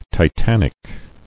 [ti·tan·ic]